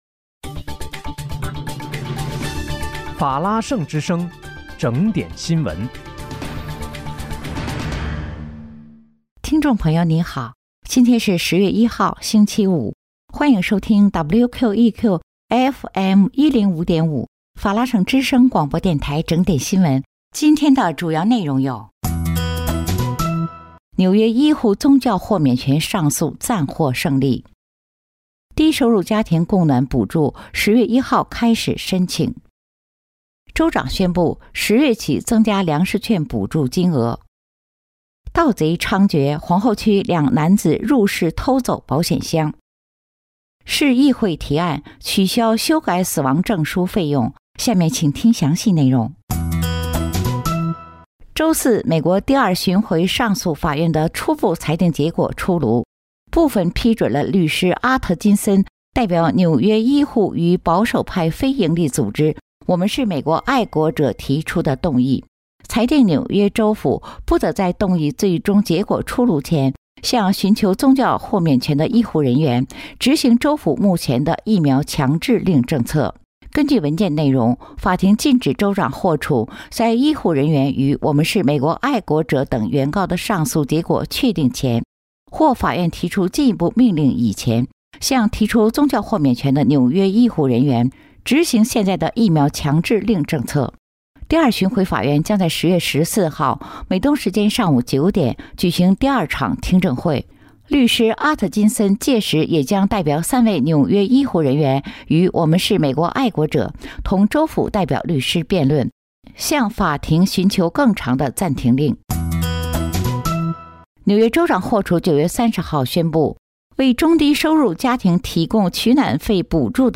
10月1日（星期五）纽约整点新闻